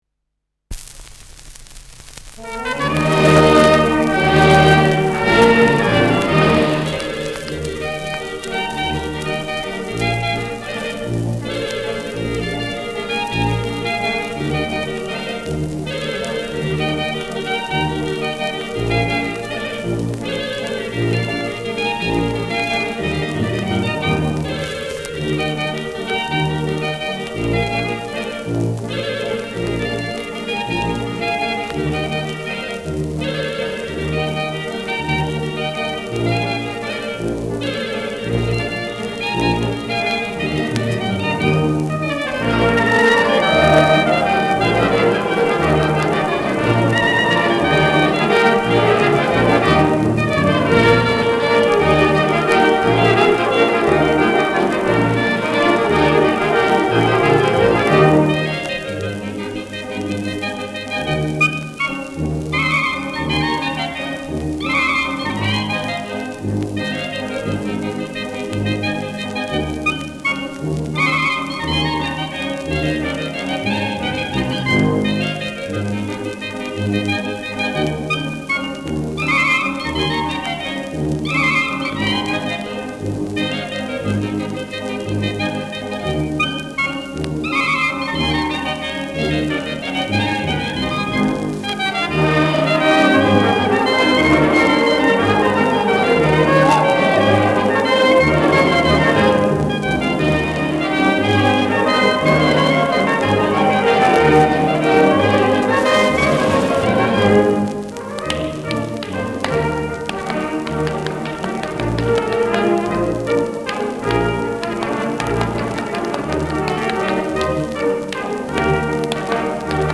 Schellackplatte
präsentes Knistern : leichtes Rauschen : leichtes Leiern
[Berlin] (Aufnahmeort)